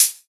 Closed Hats
Hat (62).wav